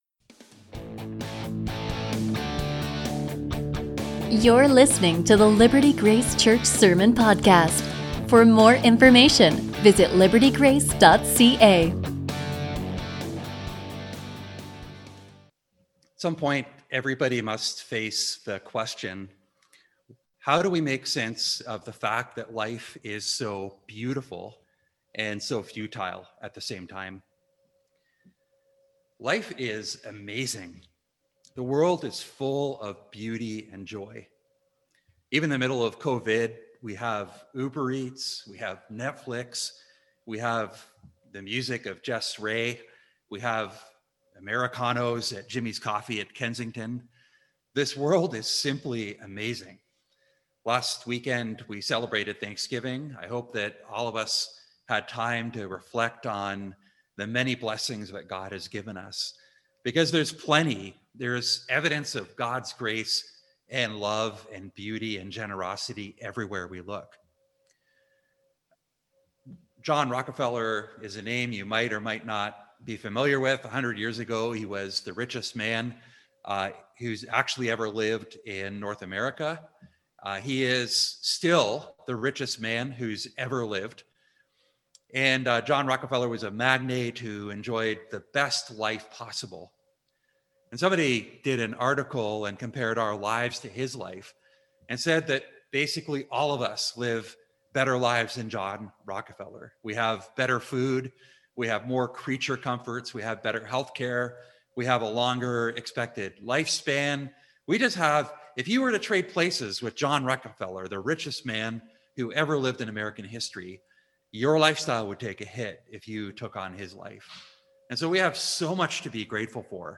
A sermon from Ecclesiastes 1:1-11